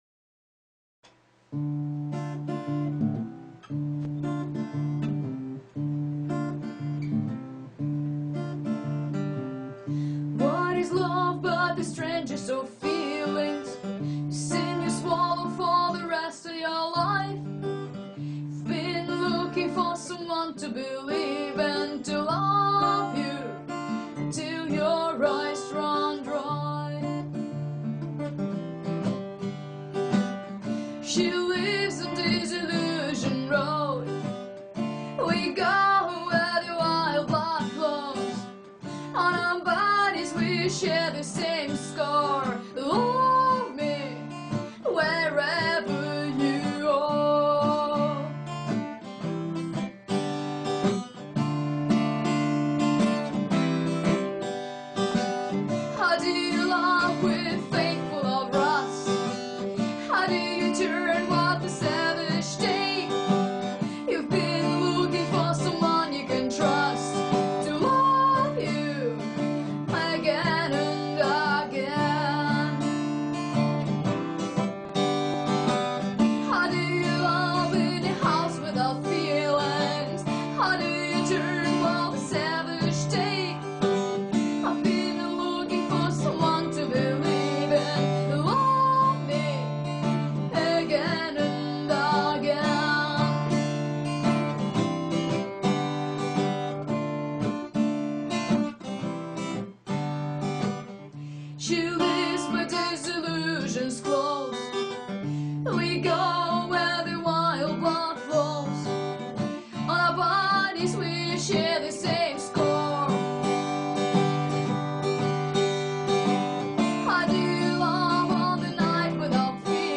cover - rehearsal
(super tense face, extreme russian accent!
Dm C G repeated throughout